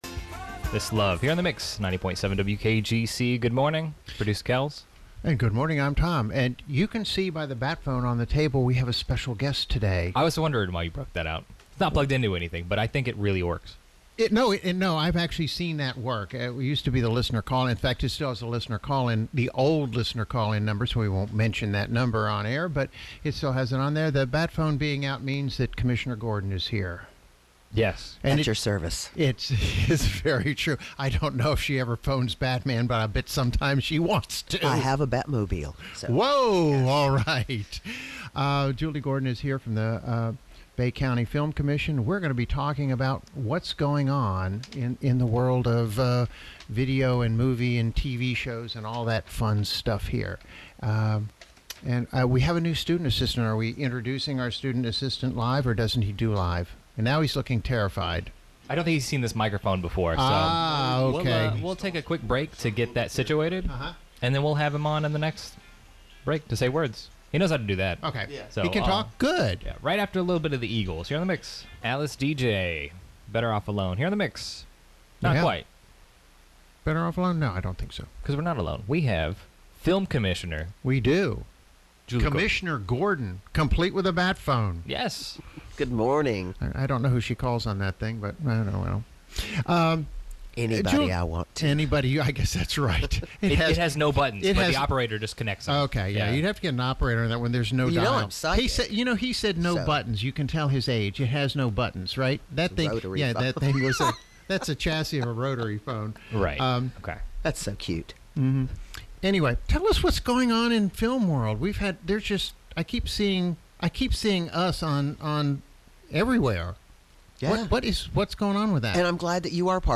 WKGC Studio
Live in the Studio